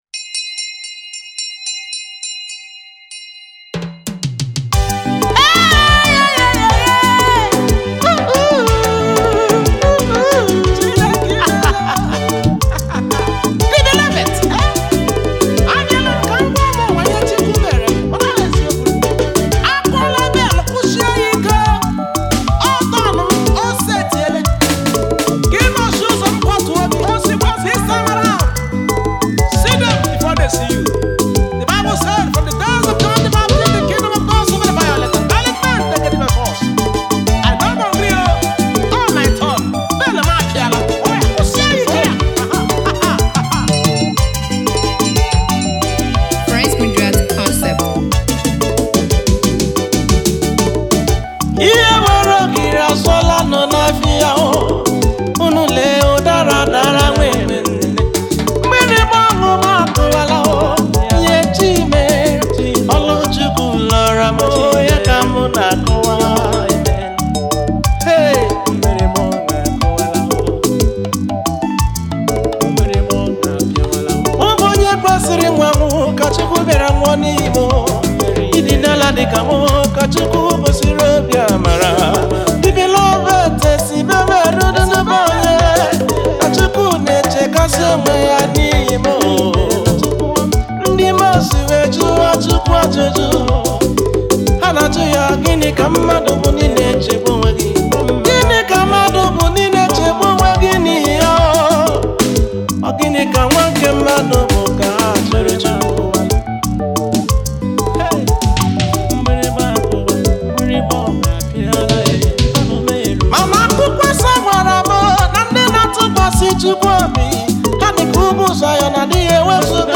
A Nigeria Igbo gospel praise